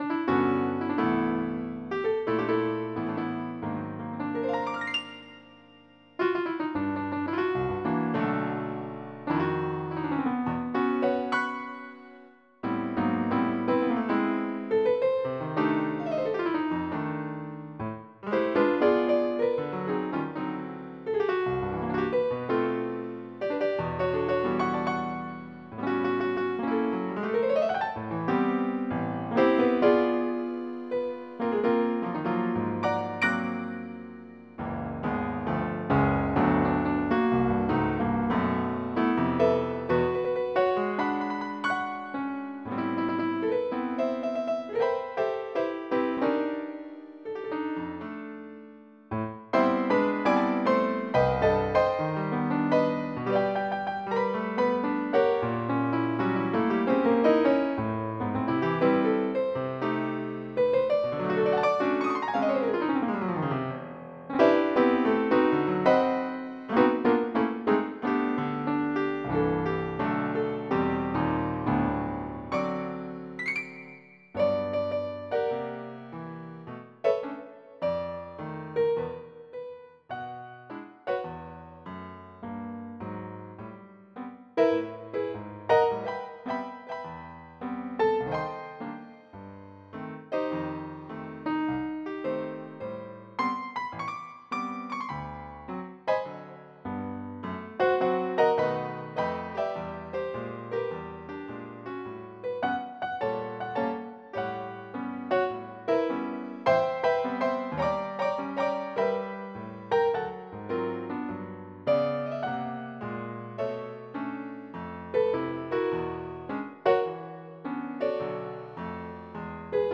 MIDI Music File
jazz14.mp3